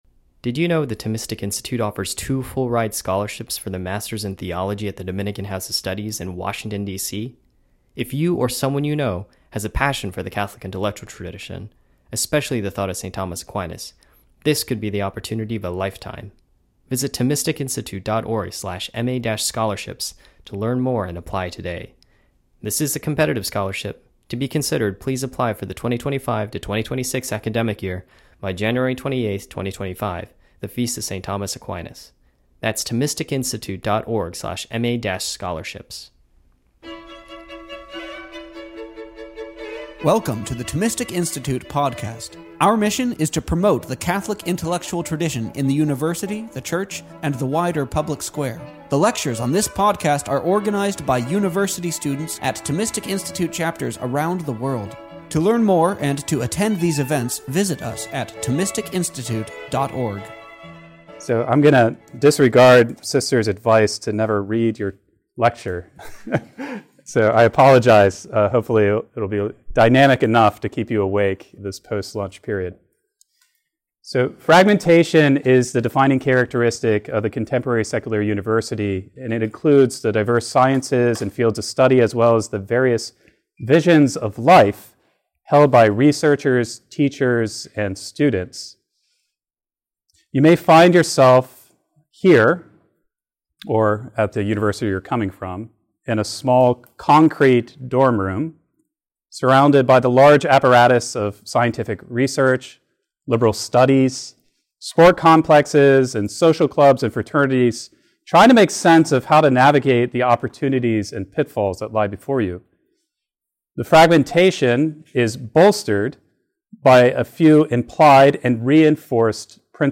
This lecture was given on October 26th, 2024, at University of South Carolina.